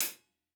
Closed Hats
TC Live HiHat 15.wav